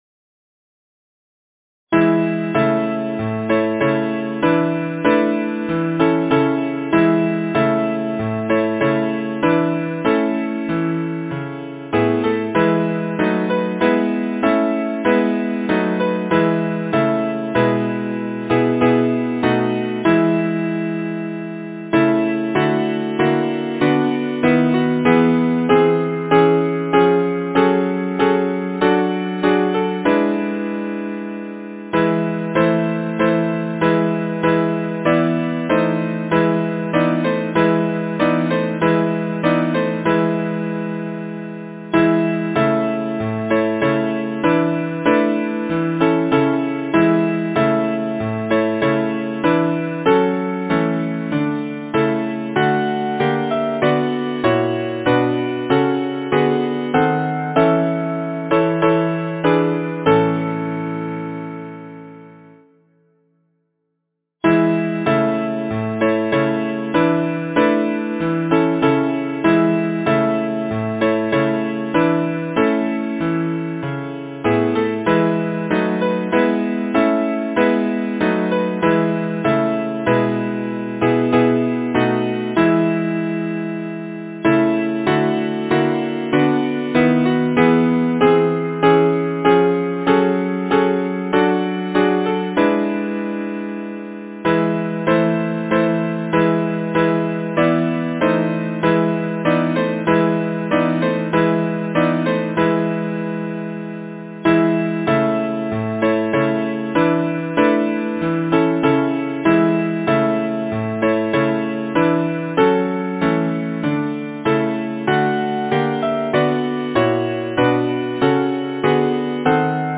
Title: Labour Composer: Henry Lahee Lyricist: Caroline Frances Orne Number of voices: 4vv Voicing: SATB Genre: Secular, Partsong
Language: English Instruments: A cappella